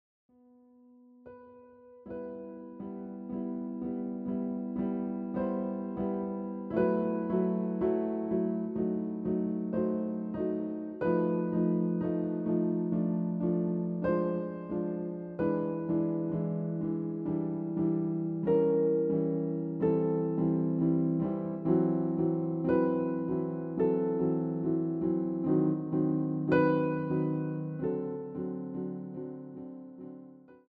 A quality audio recording of an original piano roll